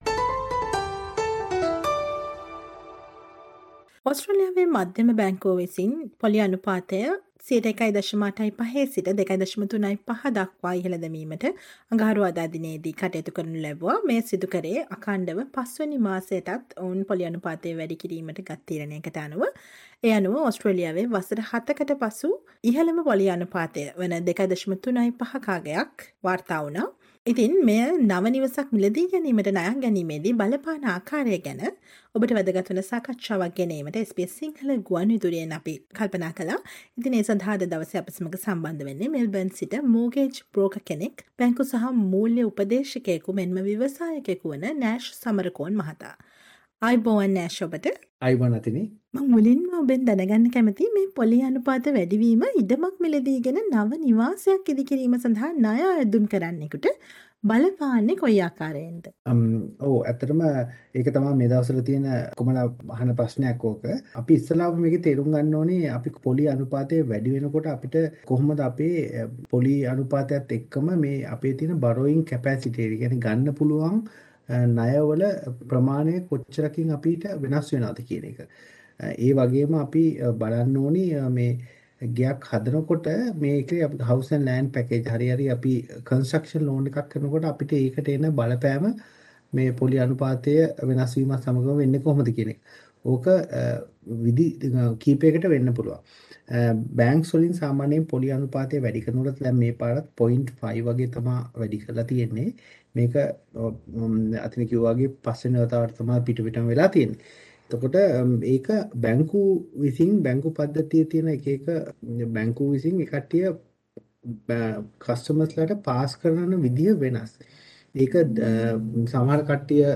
SBS Sinhala interview